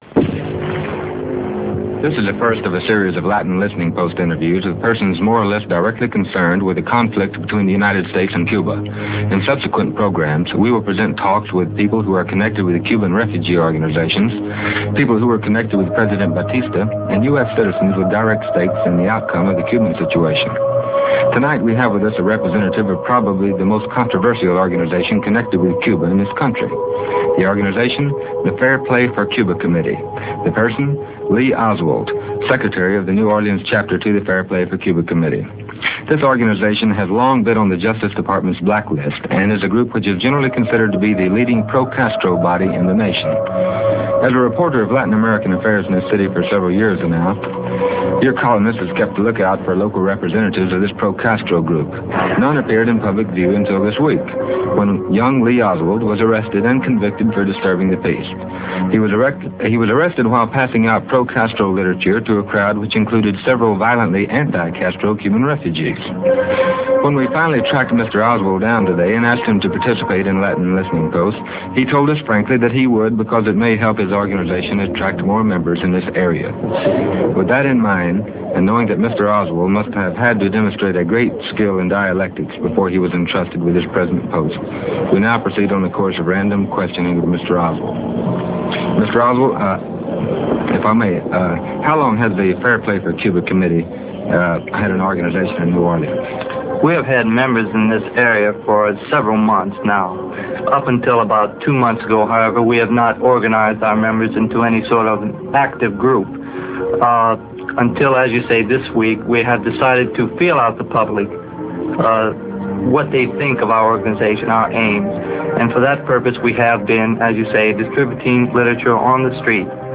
This rare radio interview of Lee Oswald shows that this man was an articulate, intelligent individual. Note how Oswald is uncomfortable with the interviewer's questioning of the true nature of his stay in Russia. Oswald intimates that he was there under the "Protection" of the U.S. Government in some form during his stay.